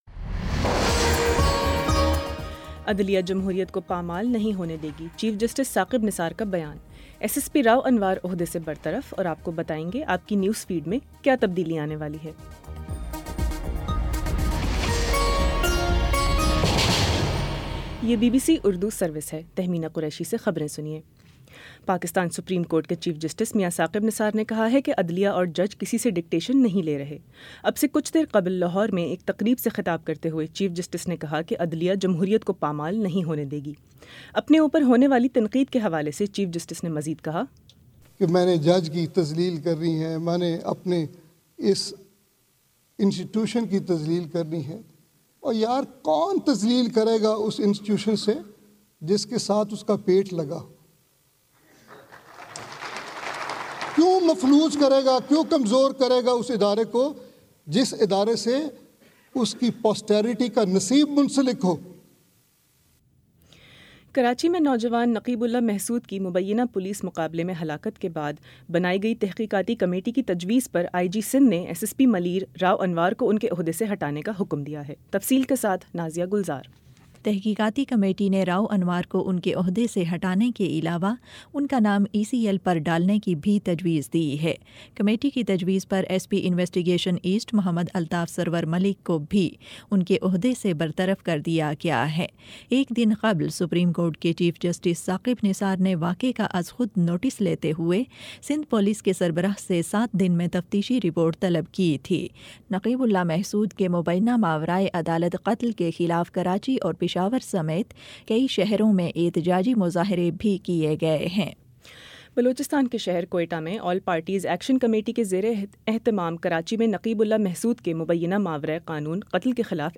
جنوری 20 : شام چھ بجے کا نیوز بُلیٹن